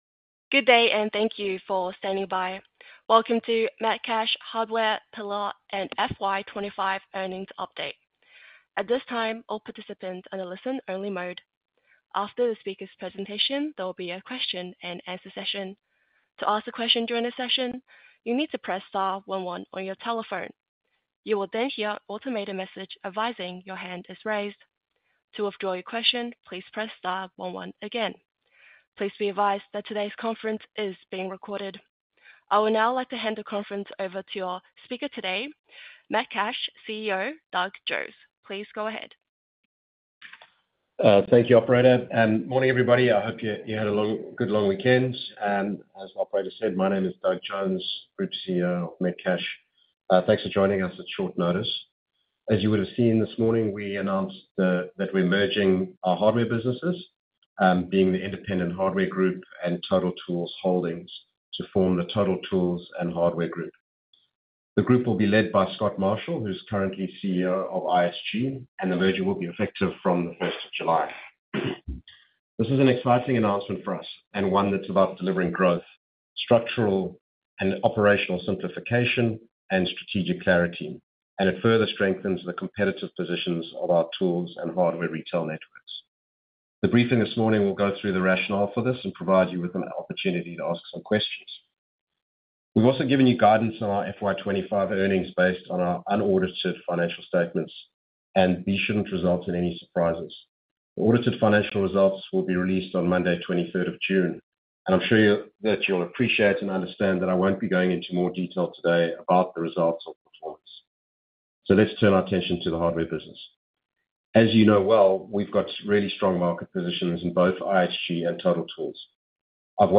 Metcash Investor Presentation Conference Call (Recording)
Metcash-Conference-call.mp3